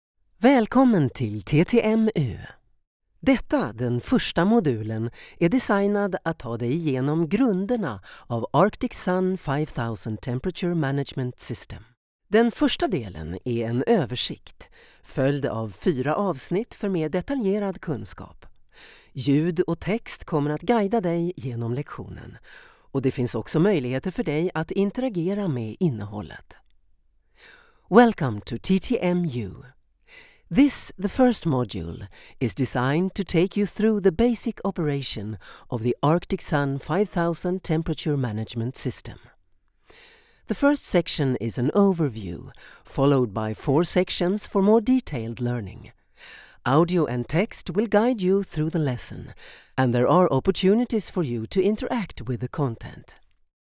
Profundo, Accesible, Maduro, Cálida
E-learning